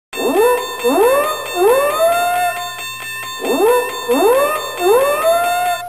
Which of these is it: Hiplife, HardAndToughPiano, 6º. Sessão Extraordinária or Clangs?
Clangs